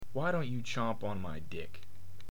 chomp